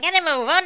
Amiga 8-bit Sampled Voice
hello.mp3